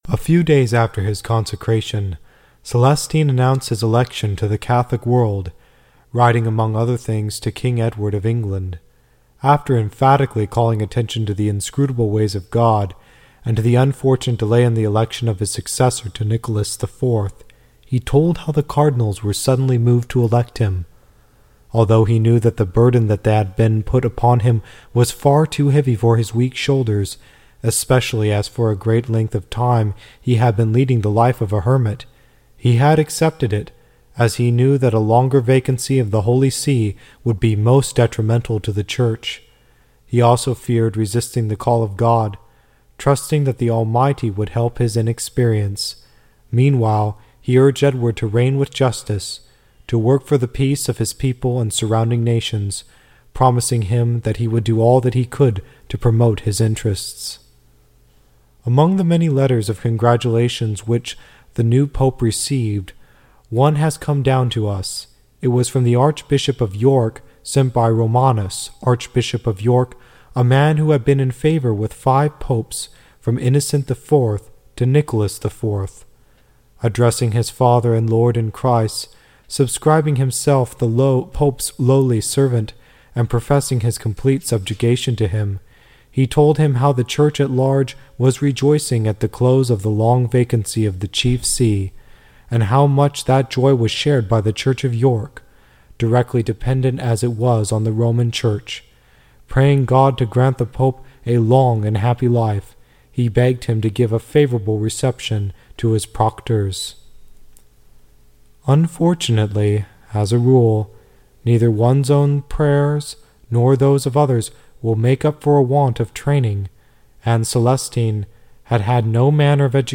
Catholic Audiobook